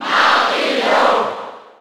Category:Crowd cheers (SSB4) You cannot overwrite this file.
Mario_Cheer_German_SSB4.ogg